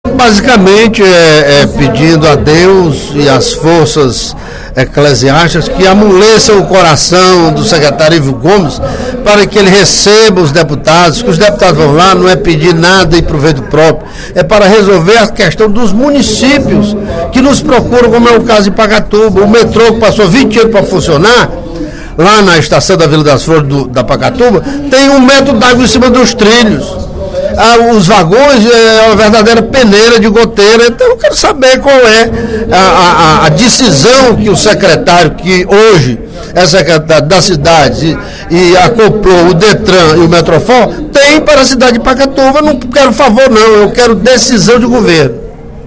O deputado Carlomano Marques (PMDB) voltou a comentar, durante o primeiro expediente da sessão plenária desta quinta-feira (12/03), a situação da saúde pública no município de Pacatuba.